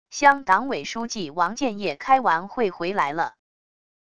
乡党委书记王健业开完会回来了wav音频生成系统WAV Audio Player